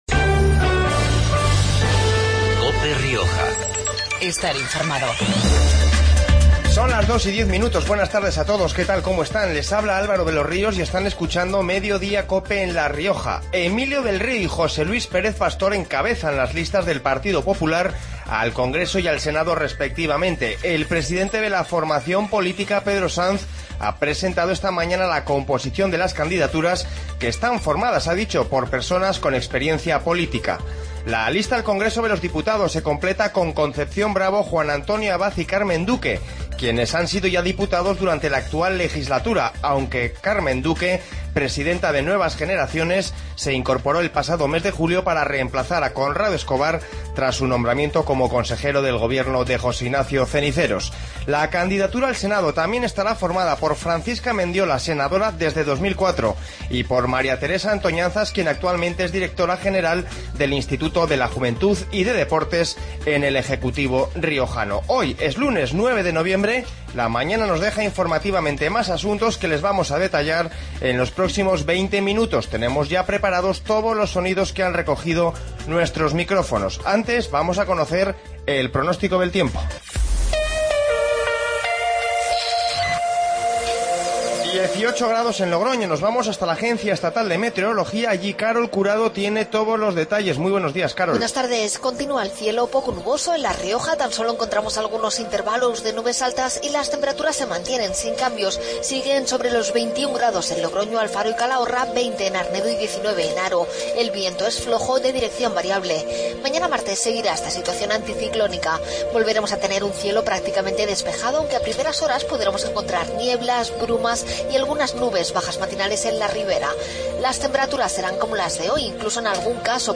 Informativo Mediodia en La Rioja 10-11-15